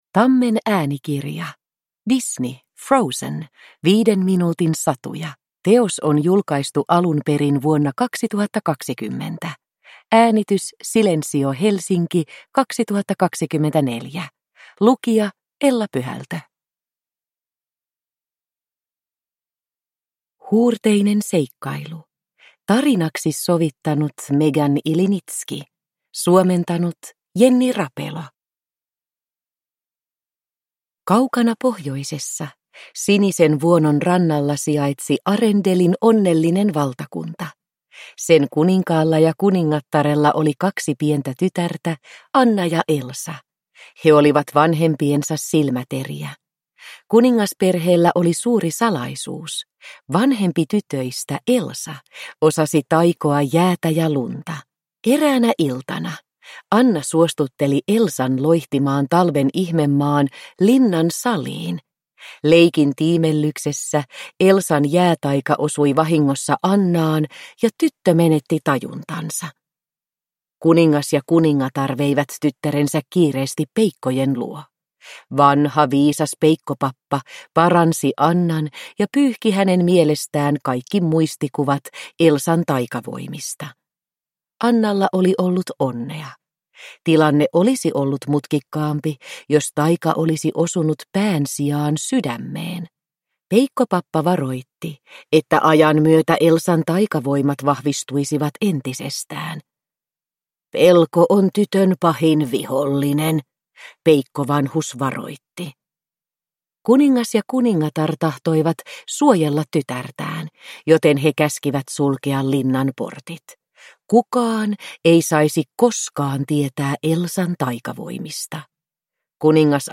Frozen. 5 minuutin satuja – Ljudbok